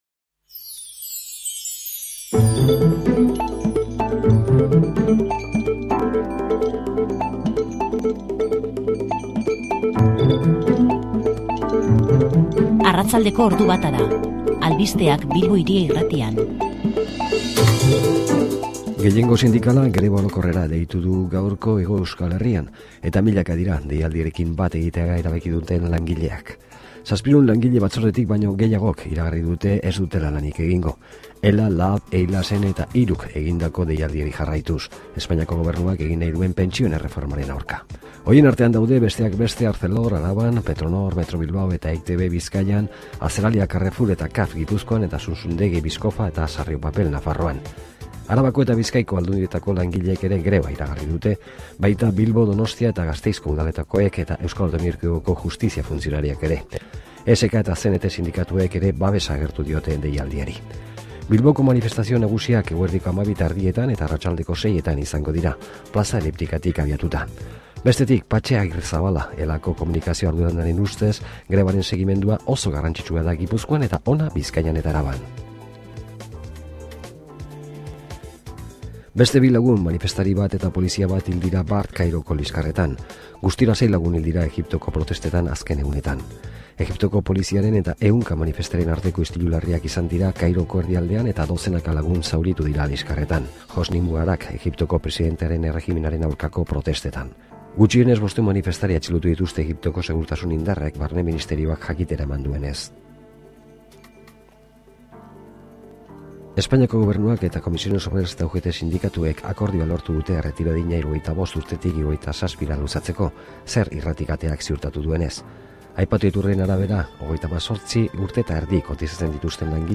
Solasaldia
GGaur hilak 27 Bilbo Hiria Irratiak bat egin du gehiengo sindikalak konbokatutako grebarekin. Hori dela kausa programazio berezia eskaini du, grebaren inguruan eta sei elkarrizketa burutu ditu.